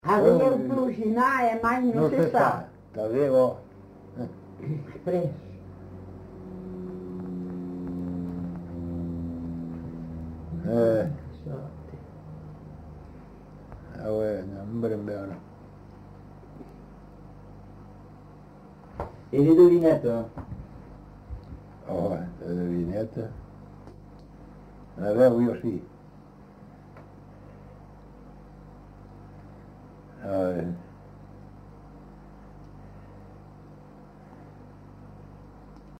Aire culturelle : Comminges
Lieu : Montauban-de-Luchon
Genre : forme brève
Effectif : 1
Type de voix : voix de femme
Production du son : récité
Classification : proverbe-dicton